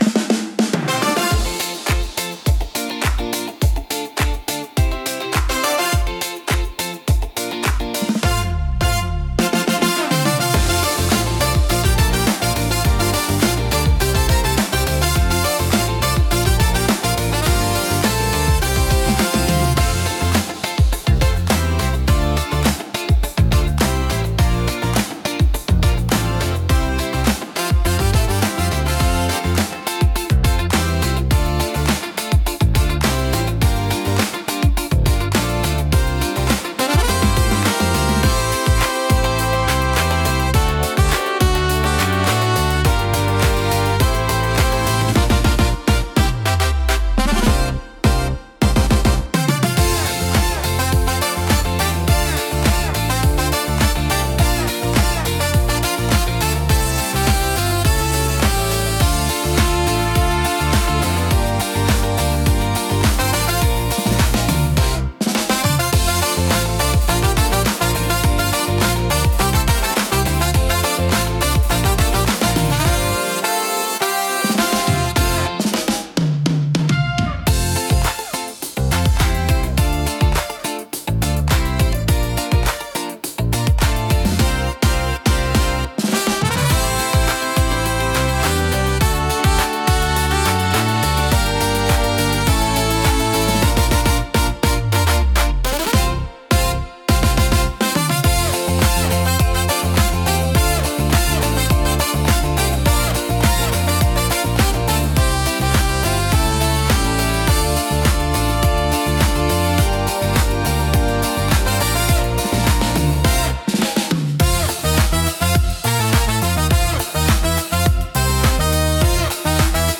明るくハッピーな曲調が特徴のジャンルです。
軽快なリズムとポップなメロディが楽しい雰囲気を作り出し、買い物やショッピング体験を爽やかに演出します。